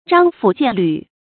章甫薦履 注音： ㄓㄤ ㄈㄨˇ ㄐㄧㄢˋ ㄌㄩˇ 讀音讀法： 意思解釋： 冠被墊在鞋子下。比喻上下顛倒。